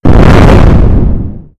scsm_explosion4w.wav